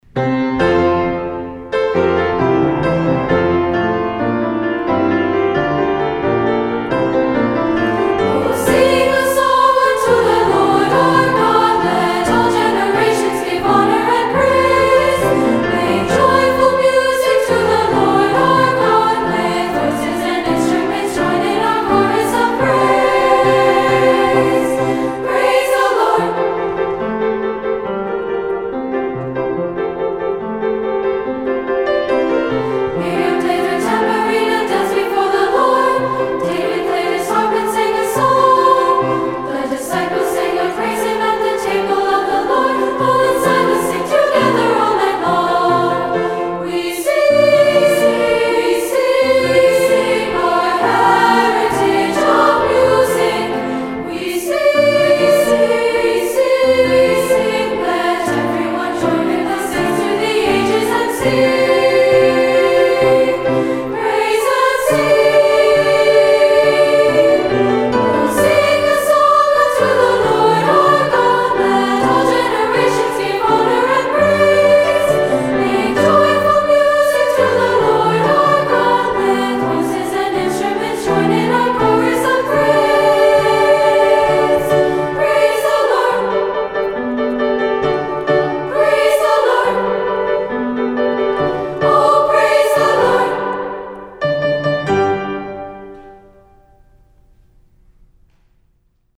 Voicing: Unison/2-Part and Piano